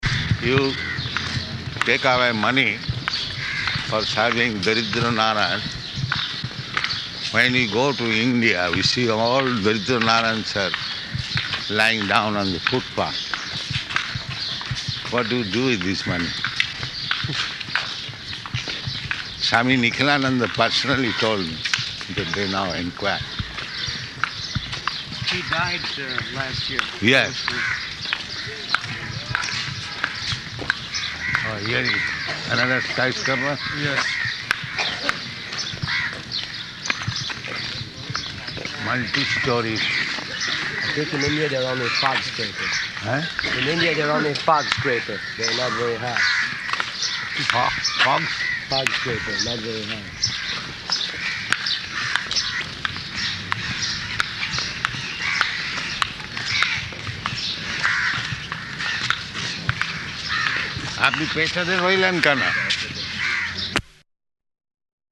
Morning Walk
Type: Walk
Location: Mayapur